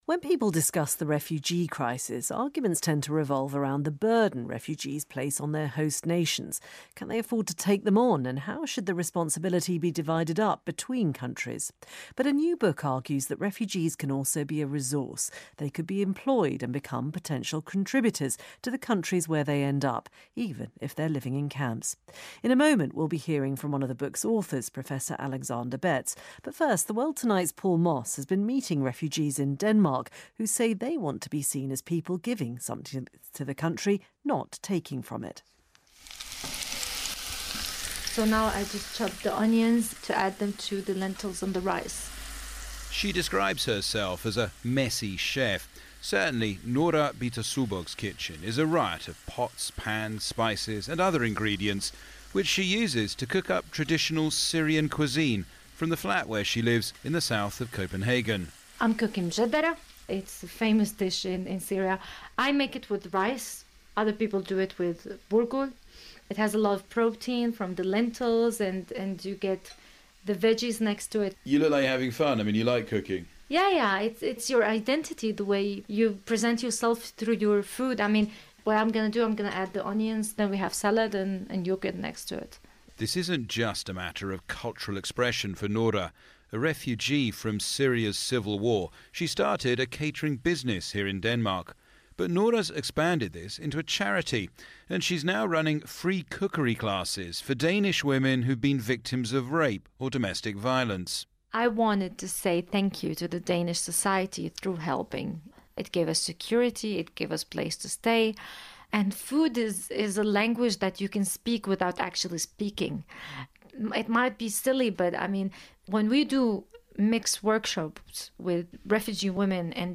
Another school of thought is to look at what refugees have contributed to their host countries, in the past, and potentially in the future. Here's my BBC Radio report about a scheme in Denmark, which seeks to help refugees become net contributors to the society which took them in.